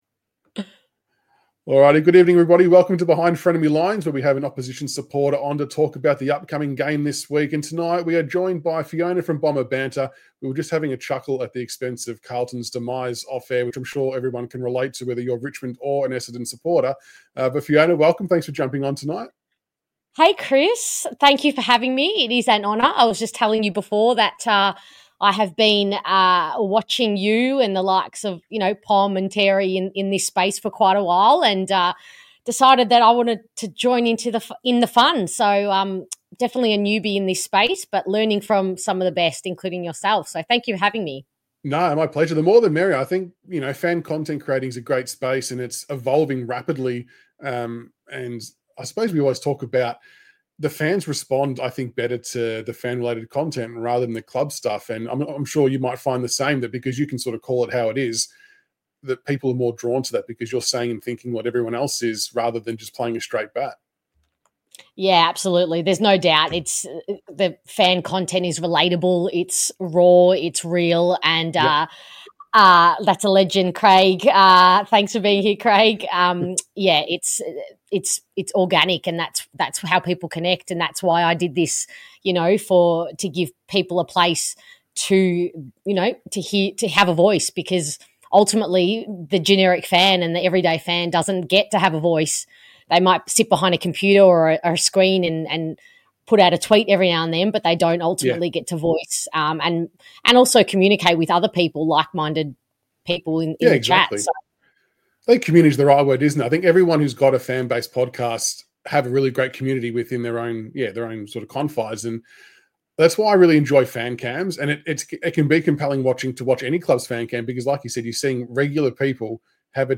LIVESHOWBehindFrenemyLinesRound18vsEssendon.mp3